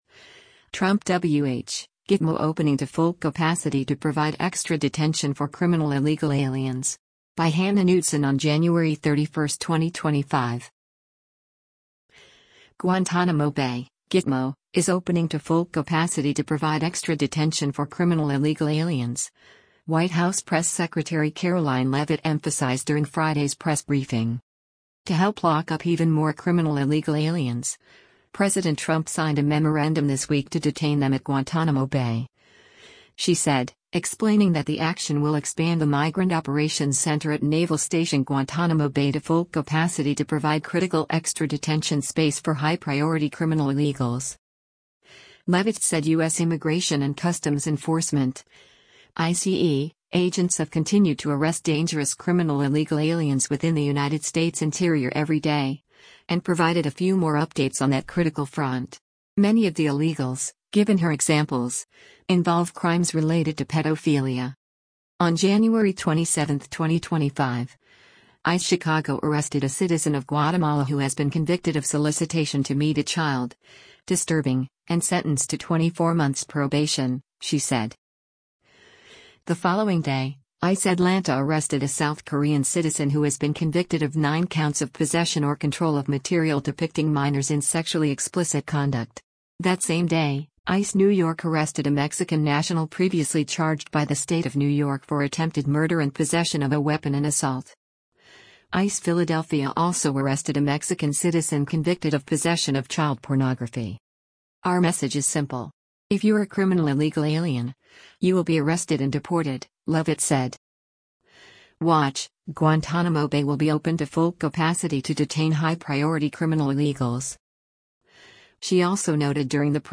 Guantánamo Bay (Gitmo) is opening to “full capacity” to provide extra detention for criminal illegal aliens, White House Press Secretary Karoline Leavitt emphasized during Friday’s press briefing.